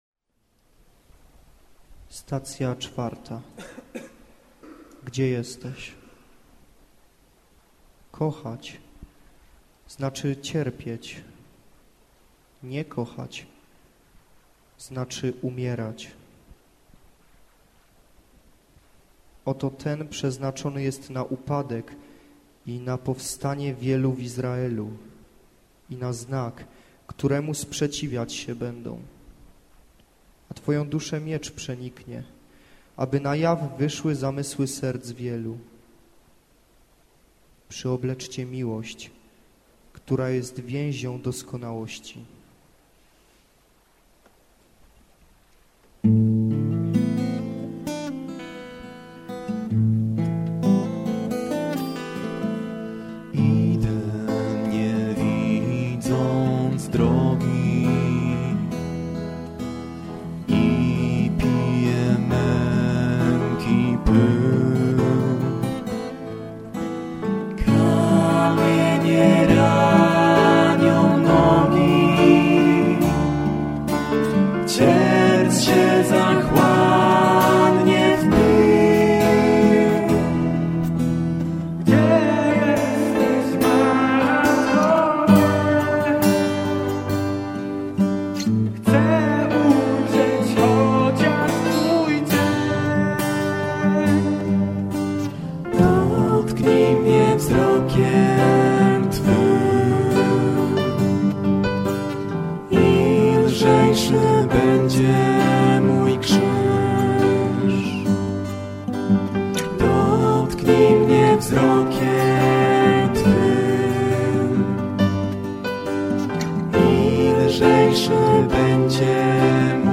WokalGitaraKeyboard